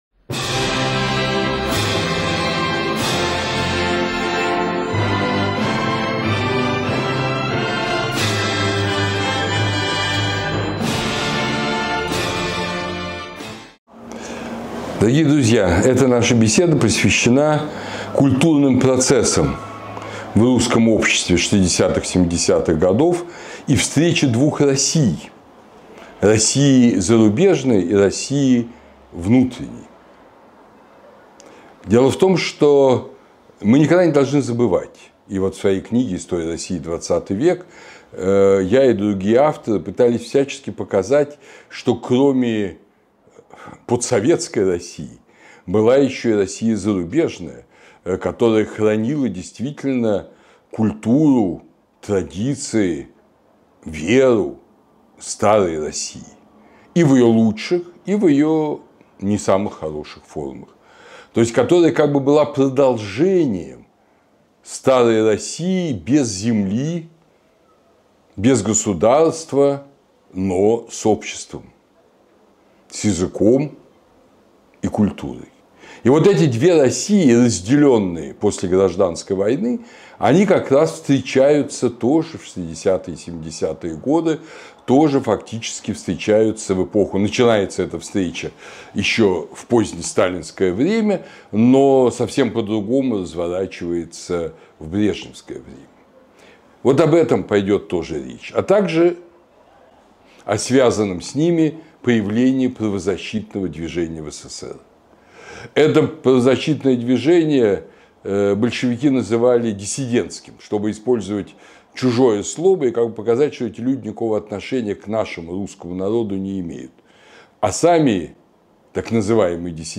Курс Андрея Зубова по новейшей истории России